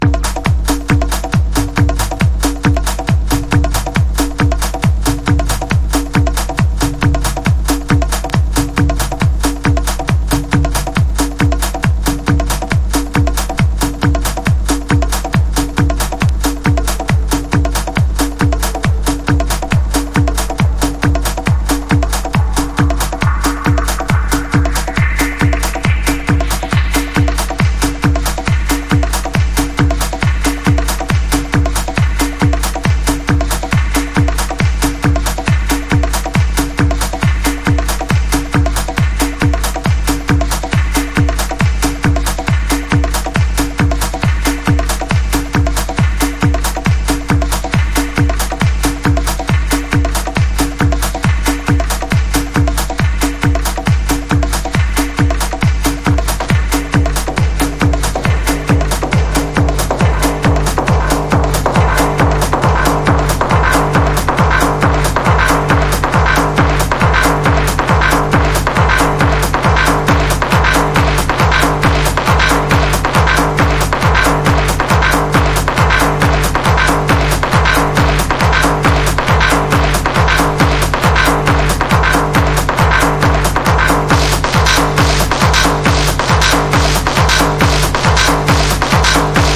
ELECTRO HOUSE / TECH HOUSE# TECHNO / DETROIT / CHICAGO